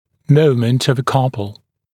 [‘məumənt əv ə ‘kʌpl][‘моумэнт ов э ‘капл]момент пары (сил)